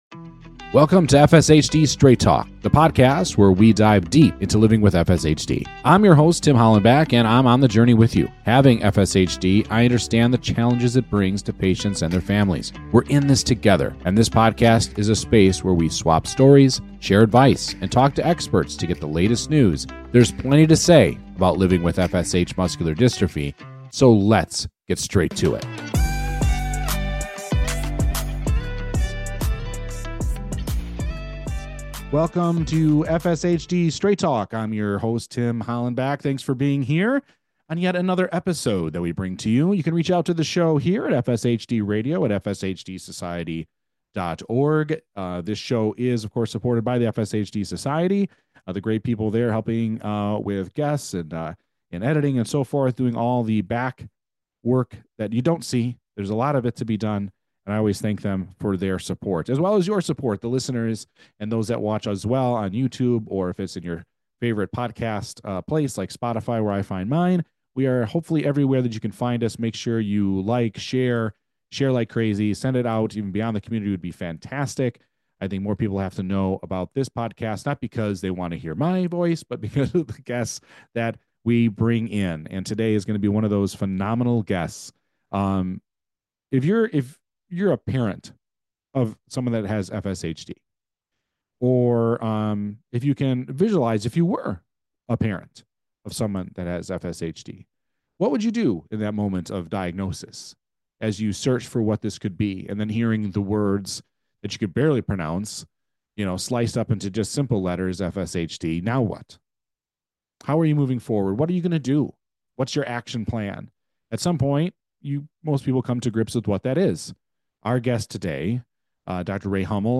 This talk show features people, advocacy, research, and more from the FSH muscular dystrophy community.